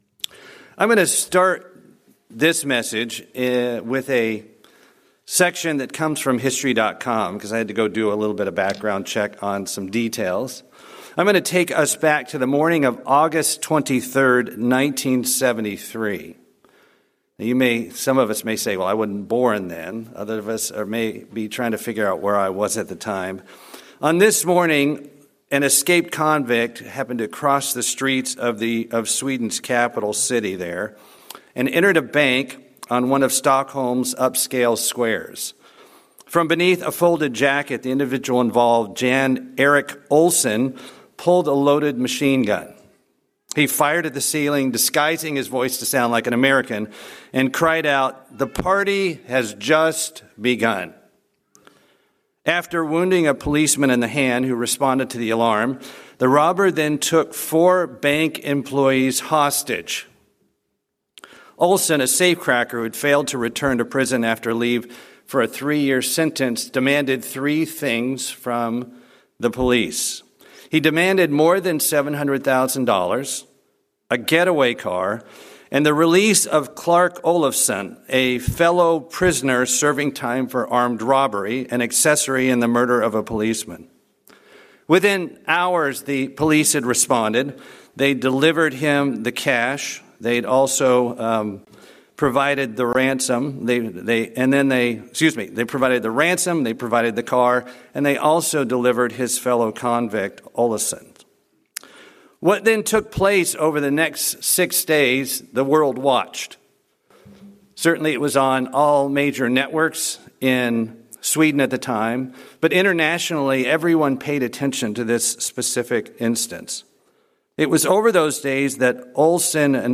The incident became the source of what is now known as the Stockholm Syndrome. This sermon connects how our minds and human nature can lead us to incorrect views of what takes place in life.
Given in Atlanta, GA Buford, GA